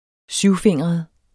syvfingret adjektiv Bøjning -, syvfingrede Udtale [ -ˌfeŋˀʁʌð ] Betydninger forsynet med syv fingre eller noget der ligner fingre Bort fra hovedvej 1, ud på den syvfingrede halvø, der kaldes Vestfjordene.